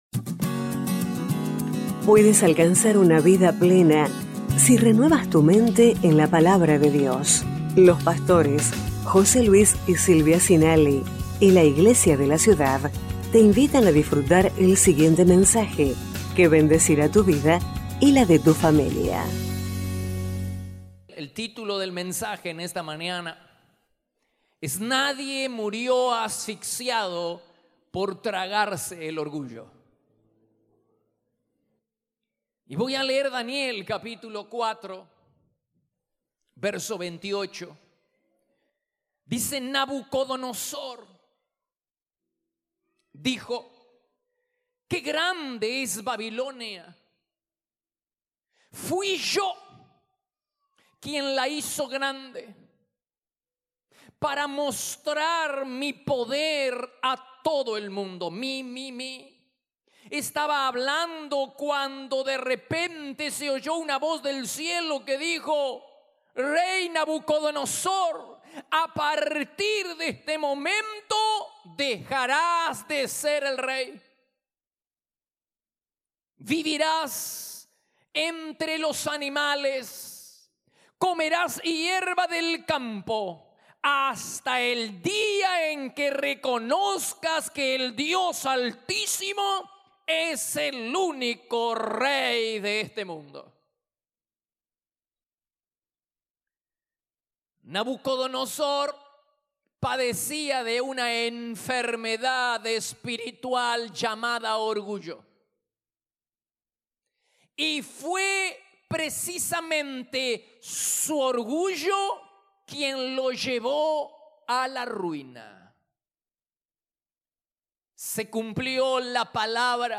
Iglesia de la Ciudad - Mensajes / Nadie murió asfixiado por tragarse el orgullo 03/02/19 (#1025)